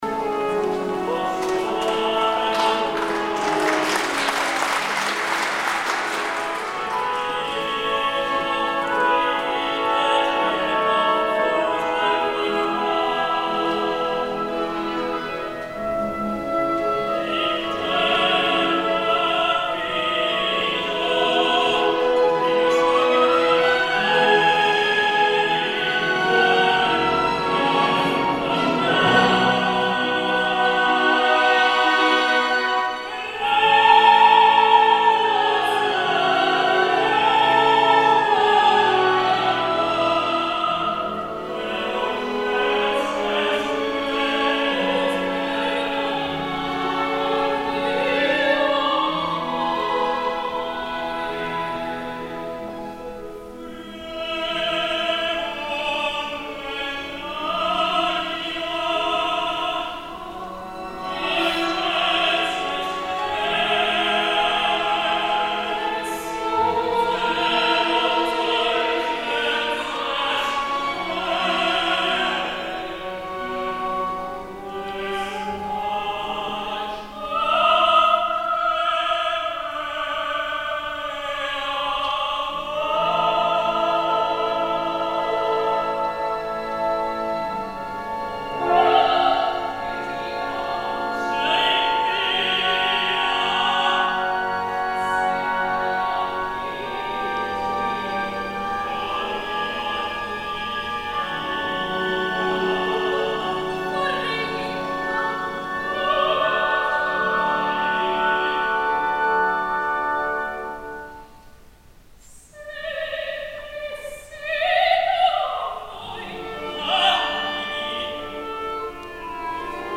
I sí, també us deixo la curiositat de La Bohème, amb un “O soave fanciulla” improbable i poc apropiat a ambdues vocalitats.
El so de l’àudio hagués pogut ser millor.
Pretty Yende, soprano
Juan Diego Flórez, tenor
HET GELDERS ORKEST
Concertgebouw, Àmsterdam 19 de maig de 2015